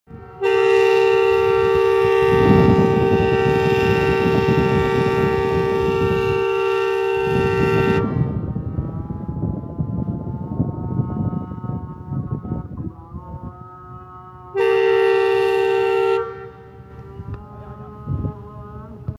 whistling+Track sound of Fareed express sound effects free download